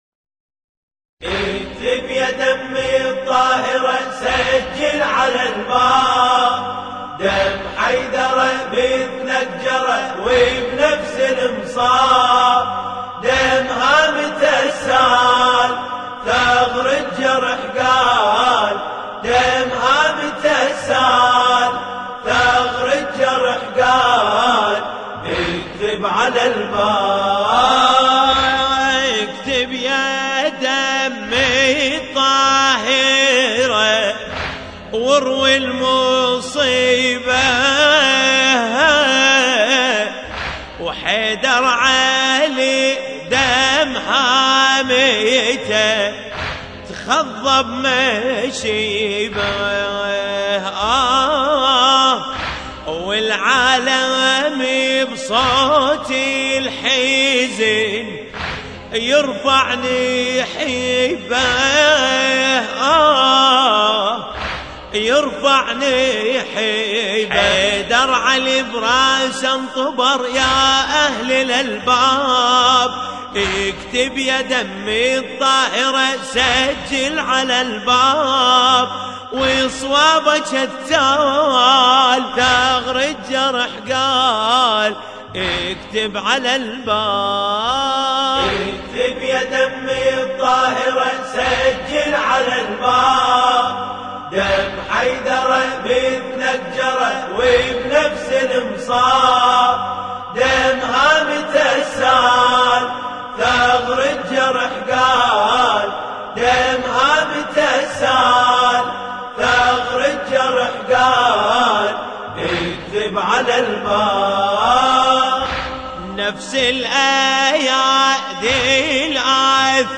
مراثي الامام علي (ع)